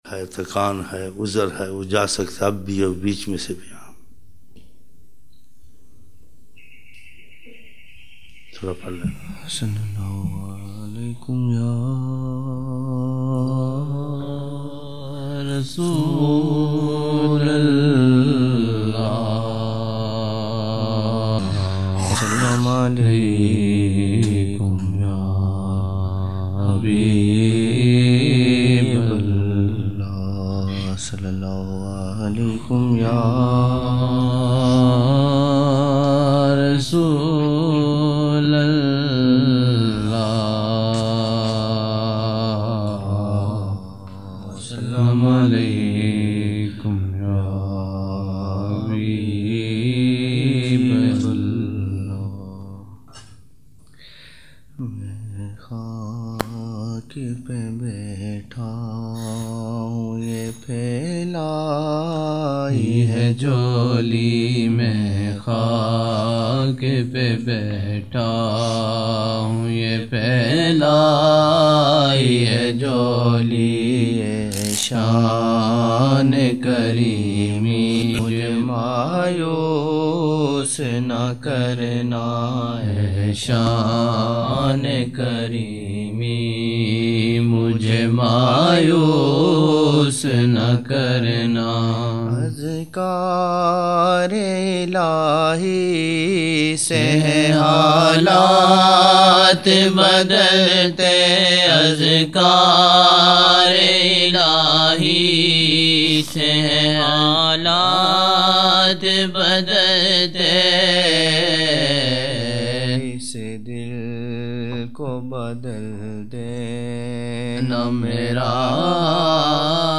26 November 1999 - Fajar mehfil (18 Shaban 1420)